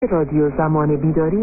Radio Zaman Bidari,  Station ID Audio
Date: October 2, 2025 / UTC: 1830-1930 / Frequency: 15540 Khz.
Station ID